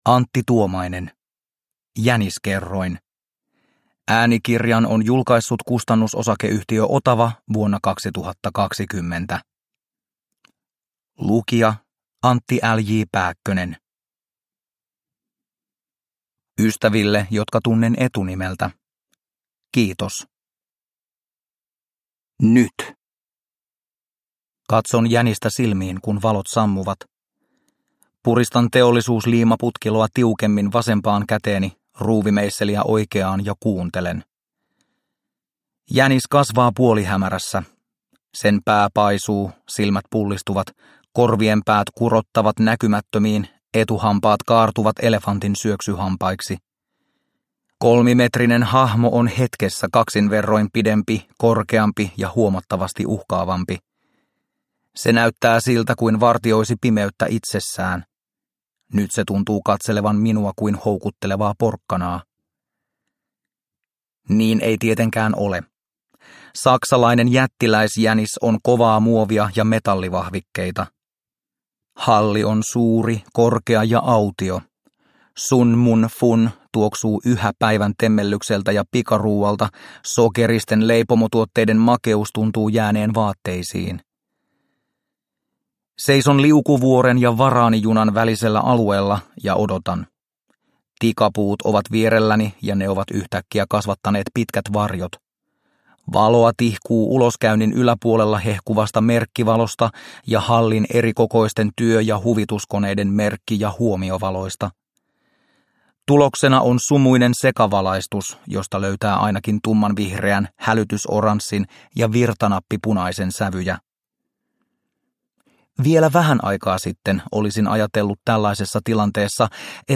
Uppläsare: Antti L.J. Pääkkönen